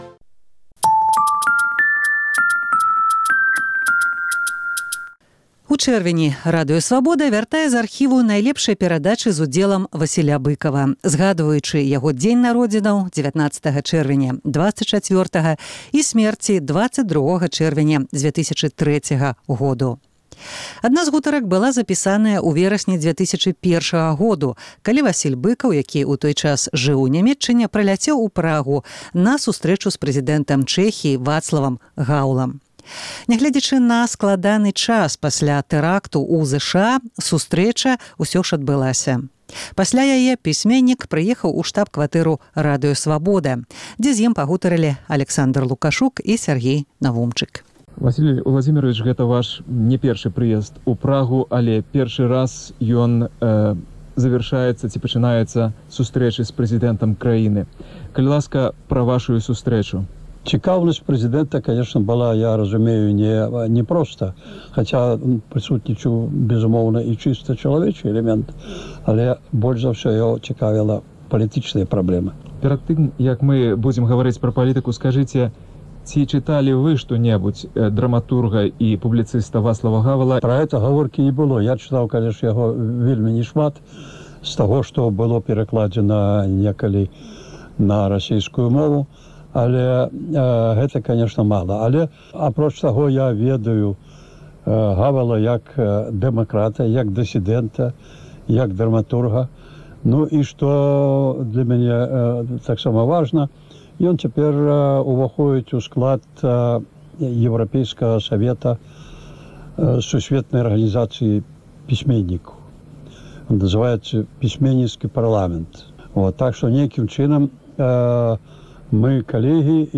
Быкаў: адно з апошніх інтэрвію на Свабодзе
Адна зь гутарак была запісаная ў верасьні 2001 году, калі Васіль Быкаў, які ў той час жыў ў Нямеччыне, прыляцеў у Прагу на сустрэчу з прэзыдэнтам Чэхіі Вацлавам Гаўлам.